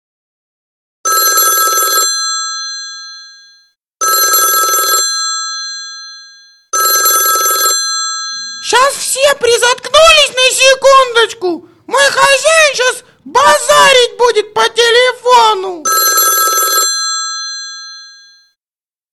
Микс - телефон